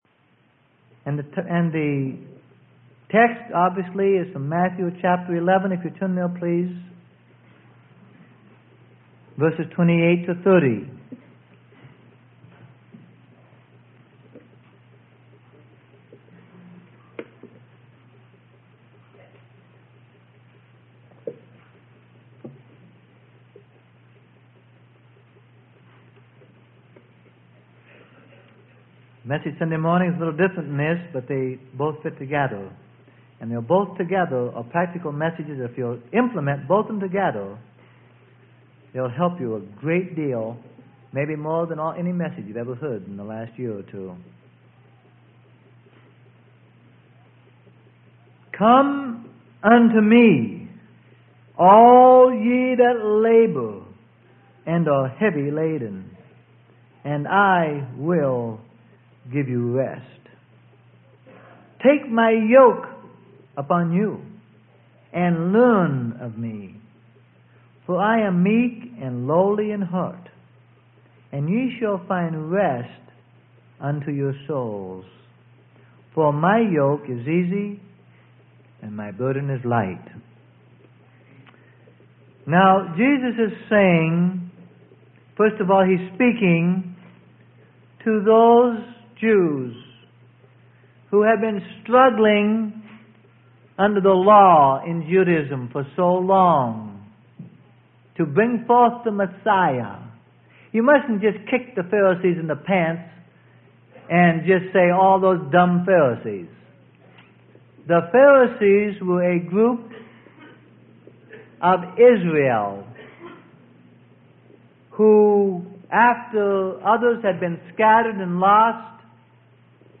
Sermon: His Burden Is Light.